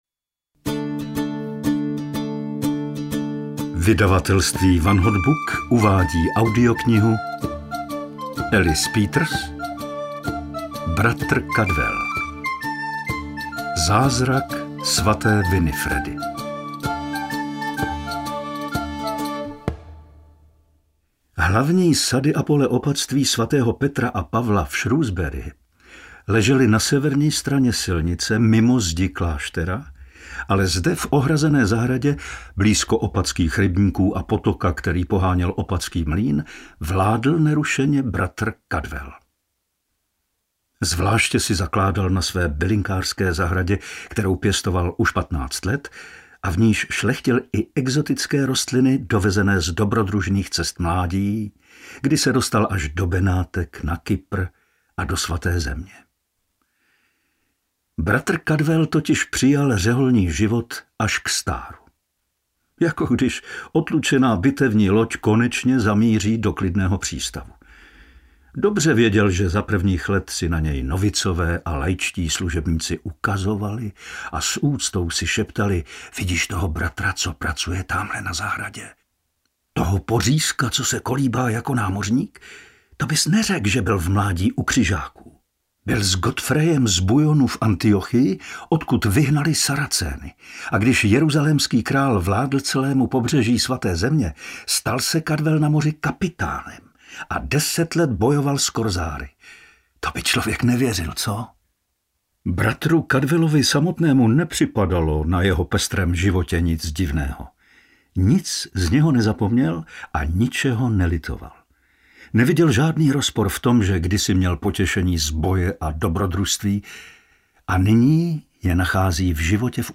Zázrak svaté Winifredy audiokniha
Ukázka z knihy
• InterpretPavel Soukup